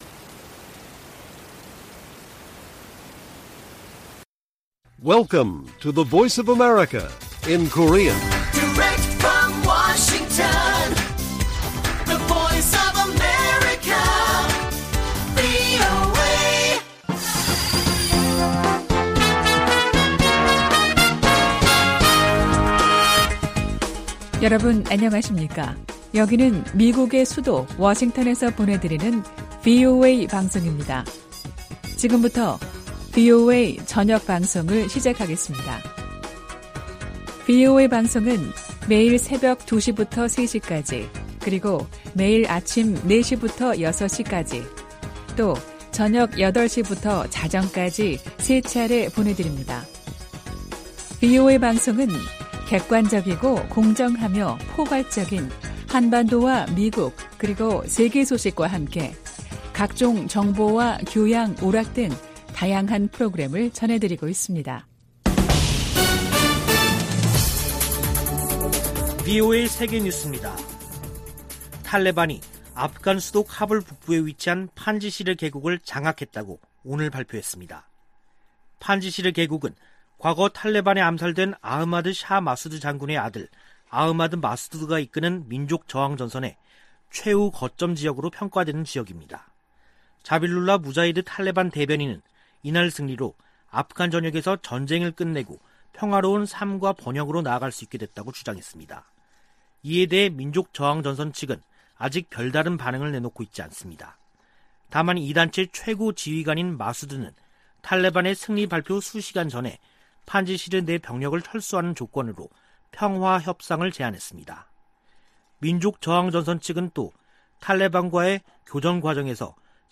VOA 한국어 간판 뉴스 프로그램 '뉴스 투데이' 1부 방송입니다. 미 공화당 의원들은 북한 문제와 관련해 강력한 압박을 촉구하고 있는 가운데 일부 민주당 의원은 강경책은 해법이 아니라고 밝혔습니다. 북한이 대륙간탄도미사일 발사를 선택하더라도 이에 맞서 임무 수행할 준비가 돼 있다고 미 북부사령관이 밝혔습니다. 북한의 사이버 위협이 진화하고 있지만 미국의 대응은 제한적이라고 워싱턴의 민간단체가 지적했습니다.